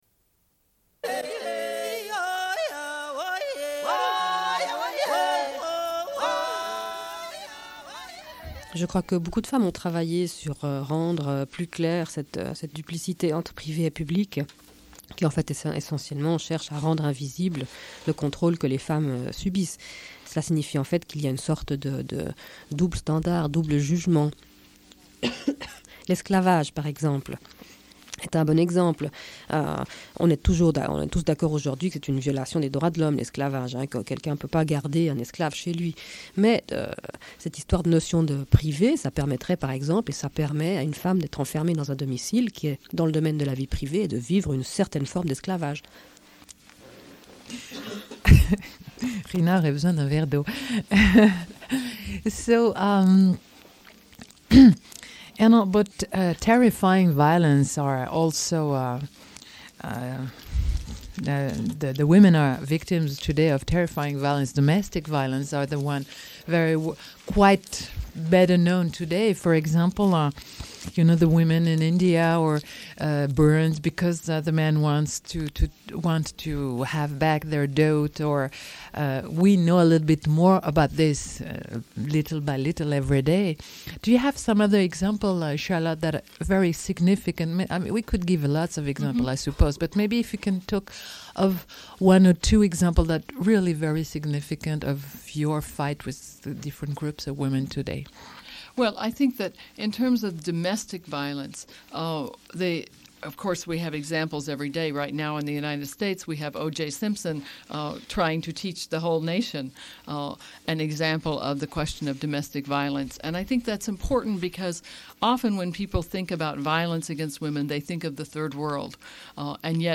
Une cassette audio, face B29:11